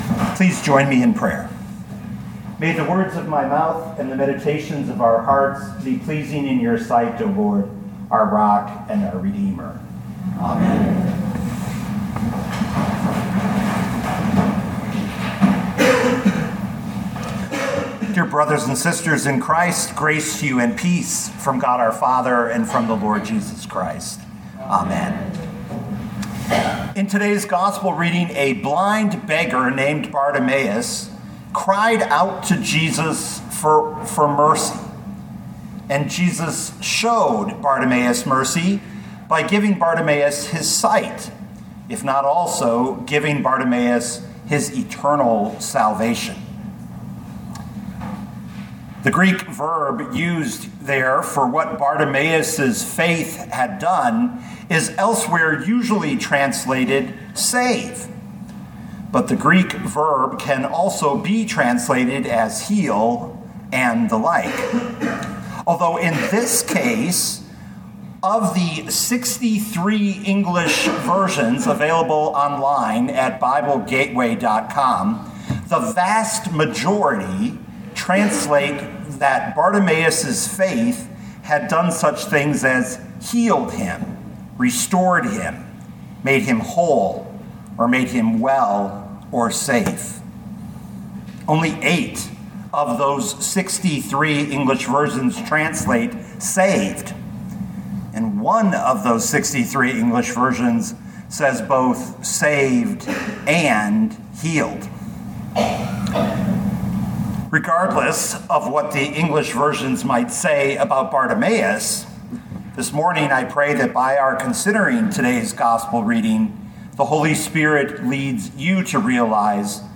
2024 Mark 10:46-52 Listen to the sermon with the player below, or, download the audio.